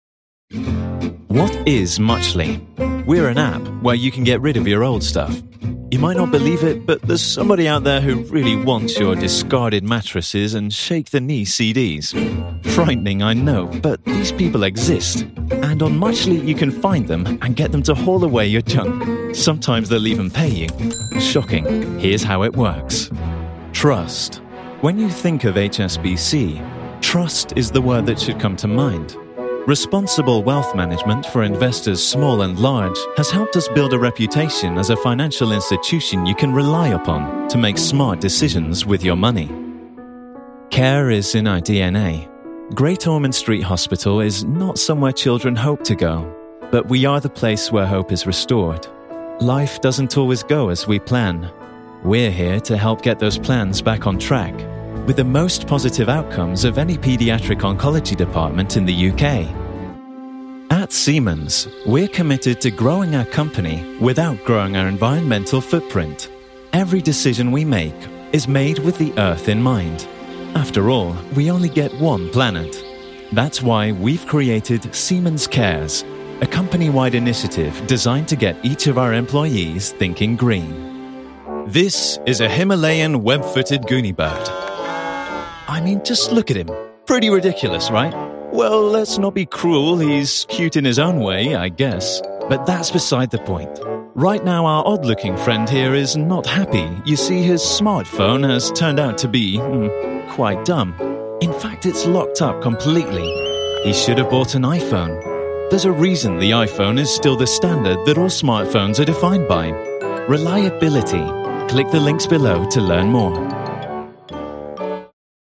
britisch
Sprechprobe: Industrie (Muttersprache):